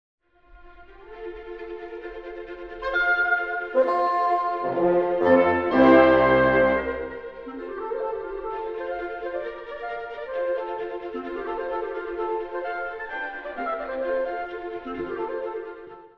↑古い録音のため聴きづらいかもしれません！（以下同様）
4~5分の短いスケルツォです。
メンデルスゾーン十八番のスケルツォですが、楽器の数が多く景色の広さを感じます。
また、バグパイプ風の旋律が現れ、どことなくスコットランド民謡を思わせます。